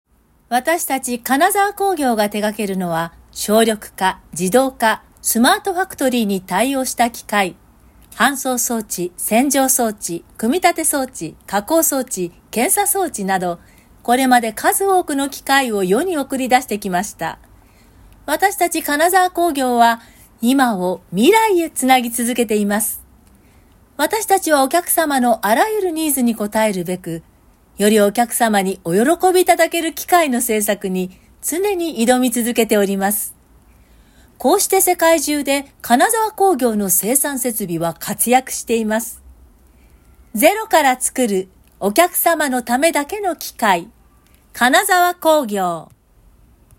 ナレーション音源サンプル　🔽
【企業説明】